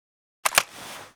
vihr_holster.ogg